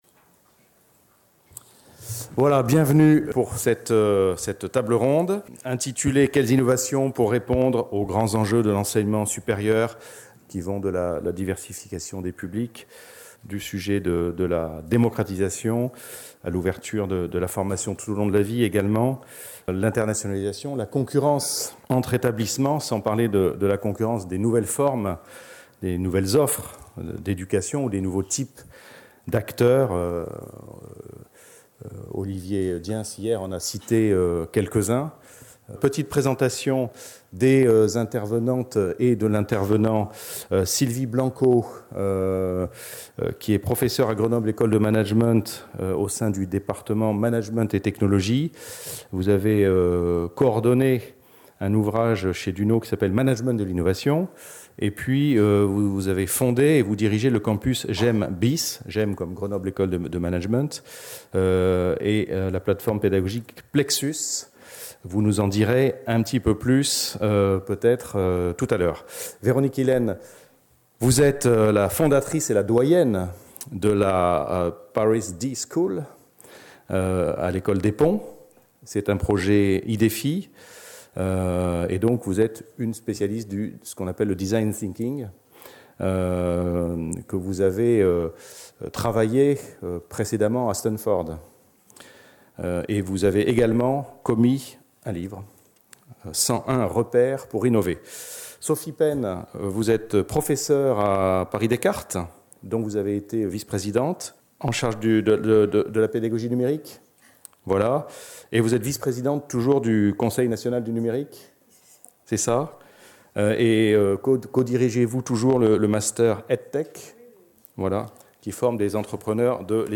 JIPES 2016 // Table ronde 2 : Quelles innovations pour répondre aux grands enjeux de l'enseignement supérieur ?